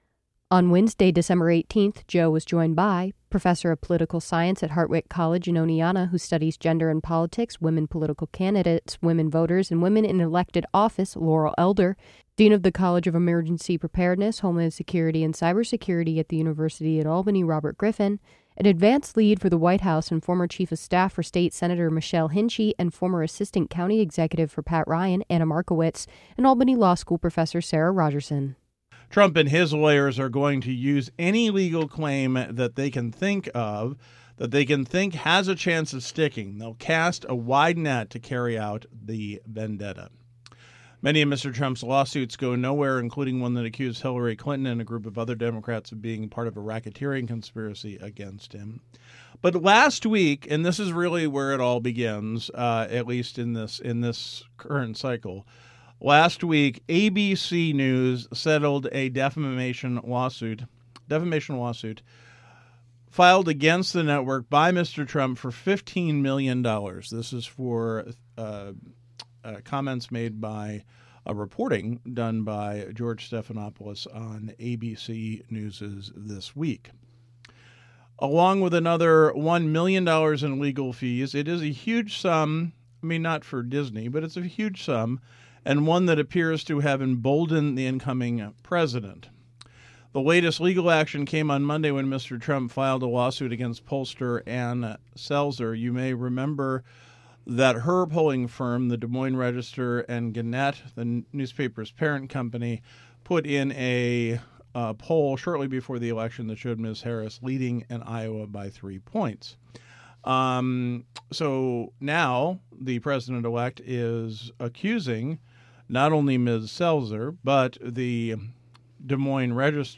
WAMC's The Roundtable is an award-winning, nationally recognized eclectic talk program. The show airs from 9 a.m. to noon each weekday and features news, interviews, in-depth discussion, music, theatre, and more!